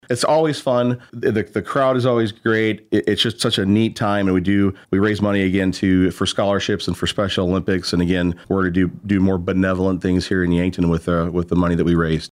Yankton Mayor and Volunteer Firefighter Mike Villanueva says the big game will pit Yankton law enforcement versus Yankton first responders and will raise money for Special Olympics and local scholarships.